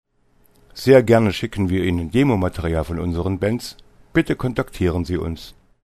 * Tanz- und Unterhaltungsmusik für alle Jahrgänge